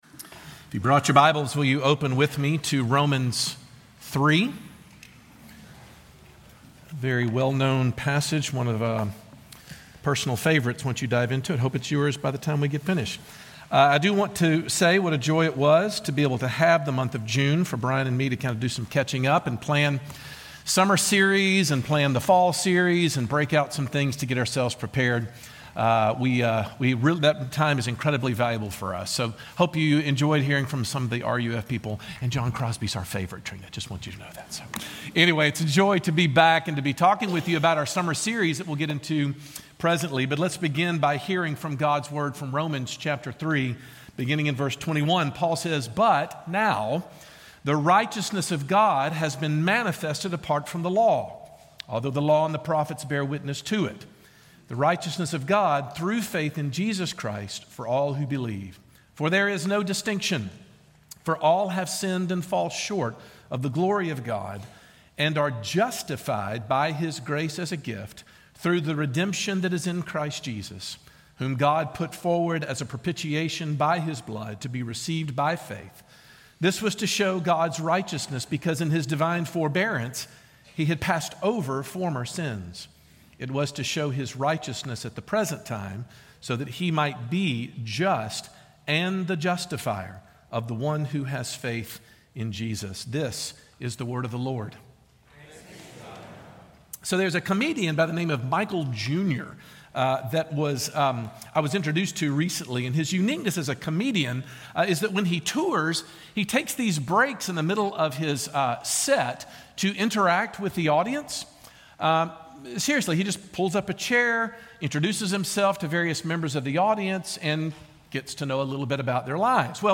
This week we look into the power of the Gospel to be the power behind everything your church does. Sermon